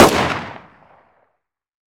/server/sound/weapons/cw_makarov/
fire2.wav